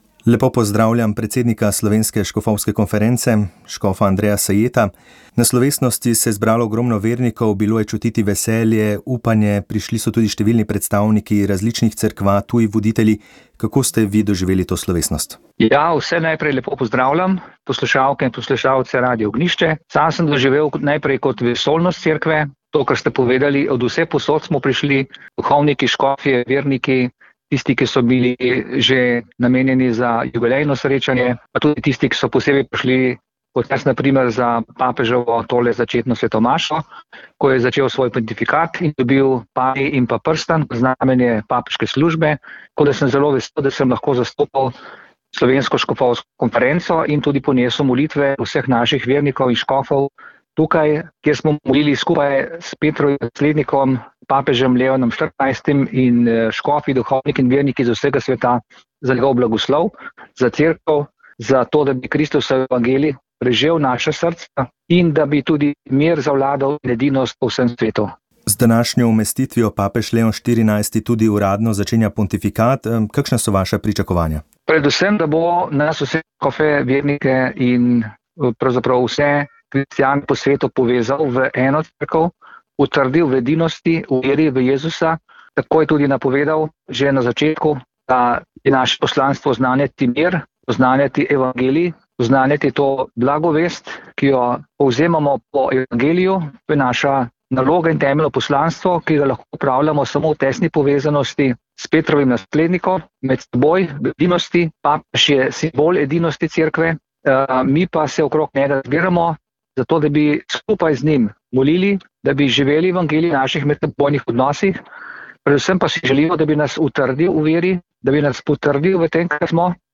O aktualnem dogajanju smo govorili z nekdanjo državno sekretarko na ministrstvu za Zdravje in zdravnico doktorico Alenko Forte.